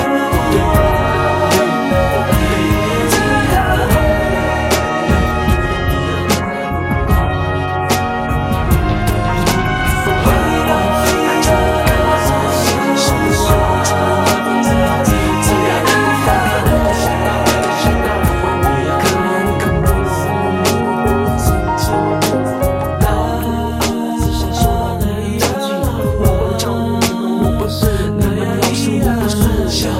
高音质立体声带和声消音伴奏